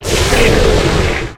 Cri de Dunaconda dans Pokémon HOME.